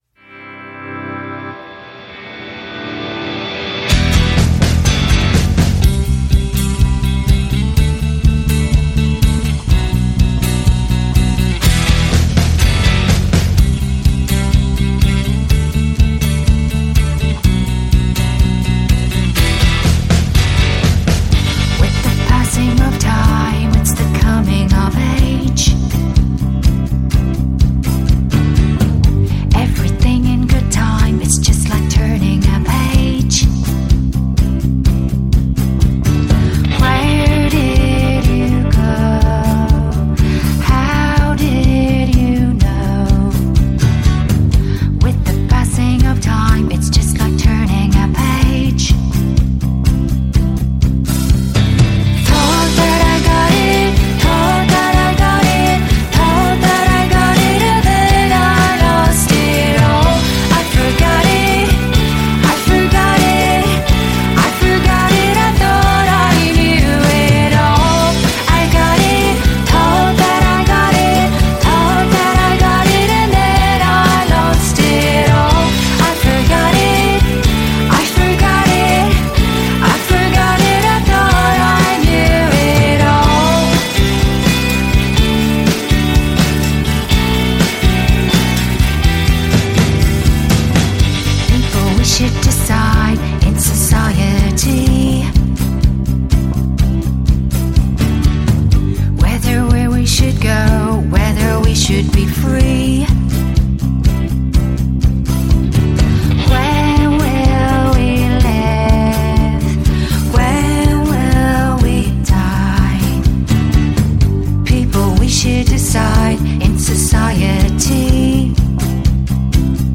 Жанр: Alternative Rock